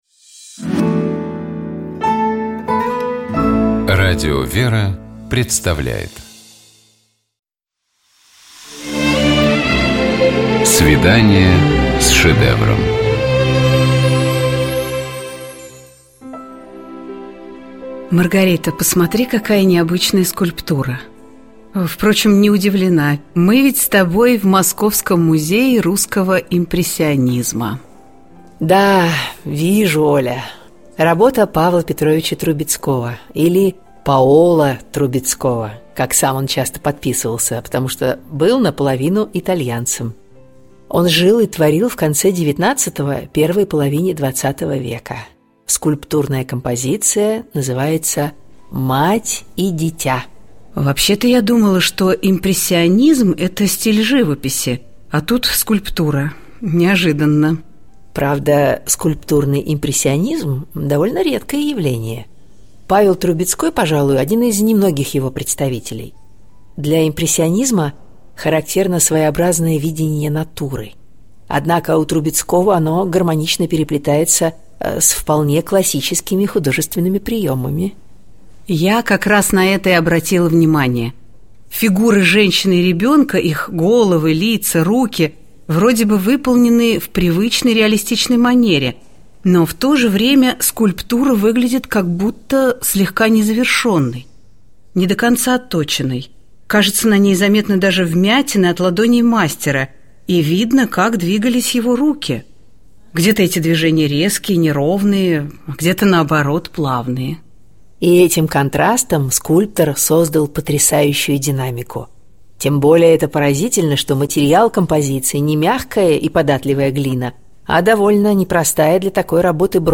Коридор картиной галереи с посетителями.